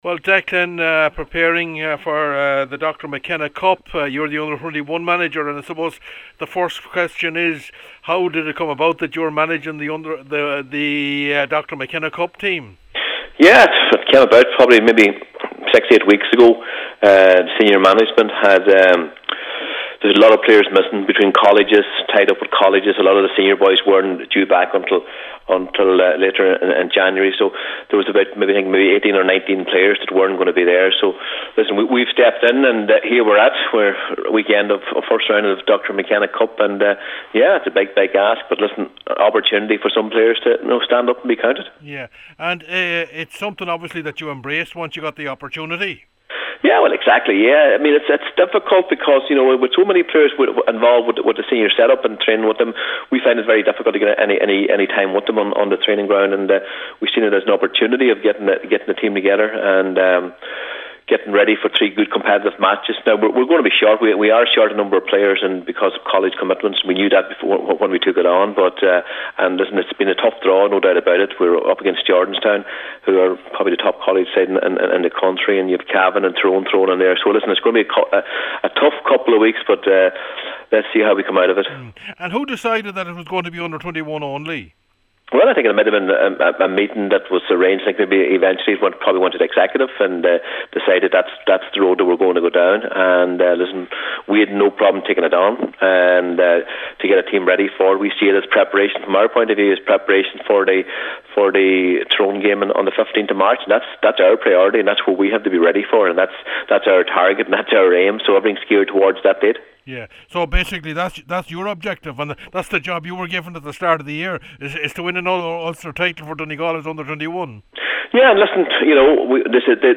spoke to Declan Bonner who feels this is a chance for Donegal’s younger players to showcase their ability…